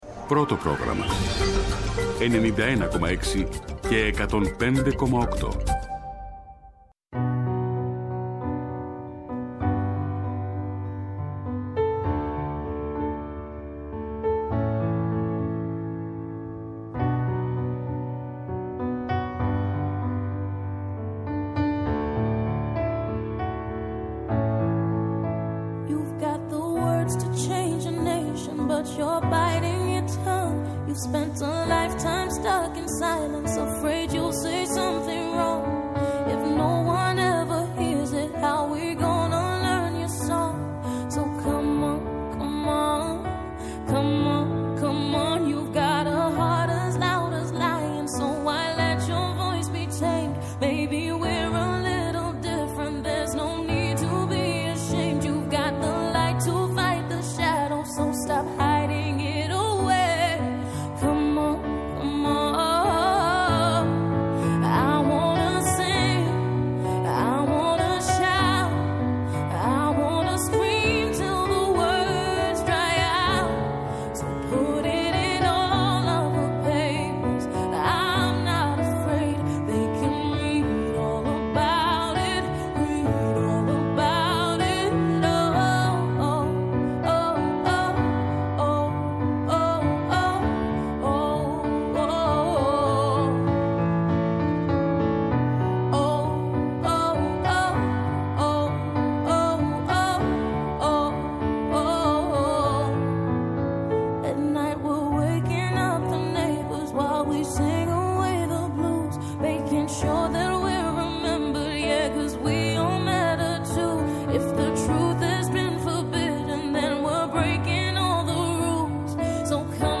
-O Χάρης Θεοχάρης, υφυπουργός Οικονομικών.